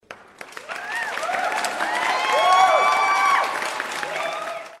Clapping Sound Button | Sound Effect Pro
Play and download the Clapping sound button for free.